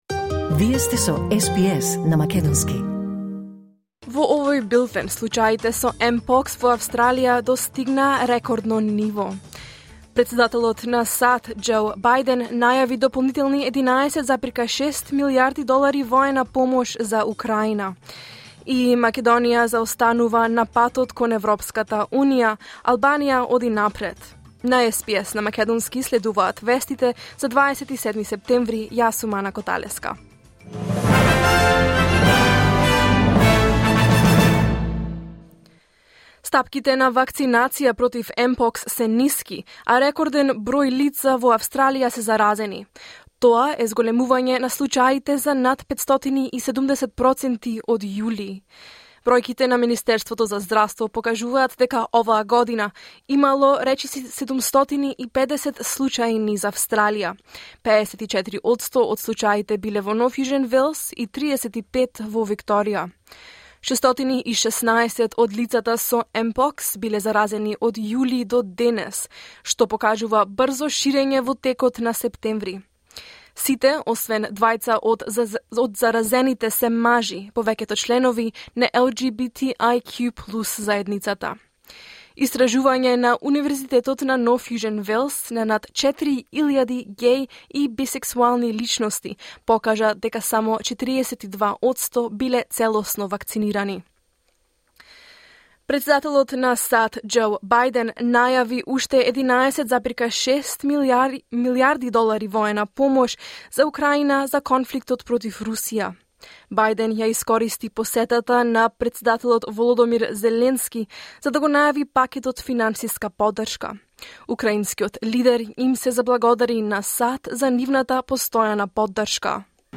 SBS Macedonian News 27 September 2024